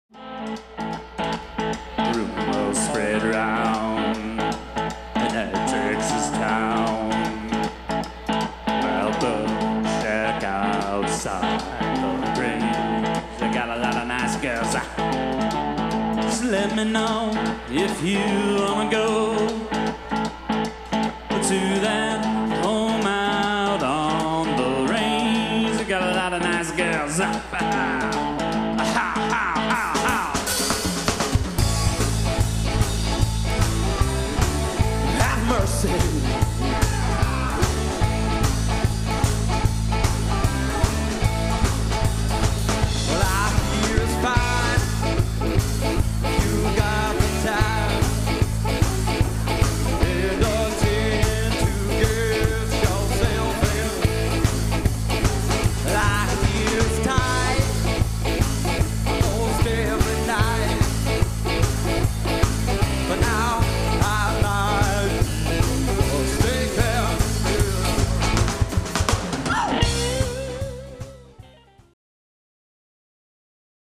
Sound clips of the band